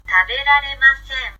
ta be ra re ma se n